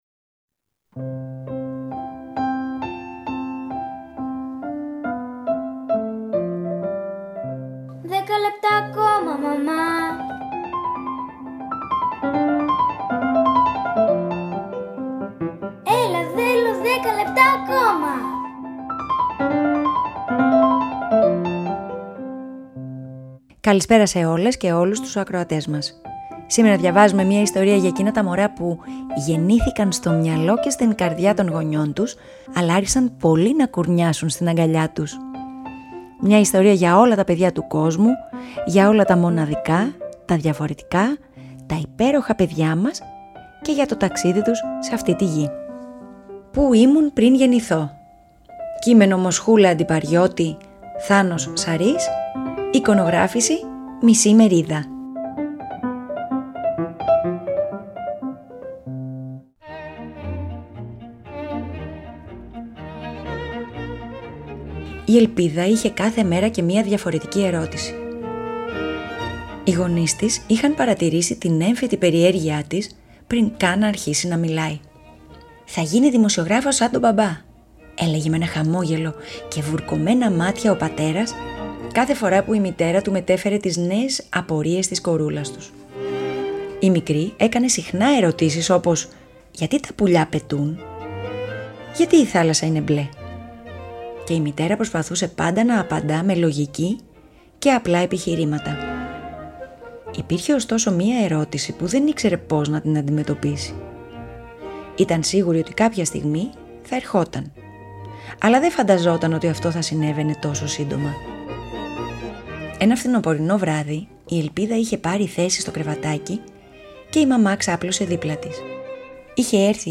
ΠΑΡΑΜΥΘΙΑ